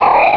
-Replaced the Gen. 1 to 3 cries with BW2 rips.
gulpin.aif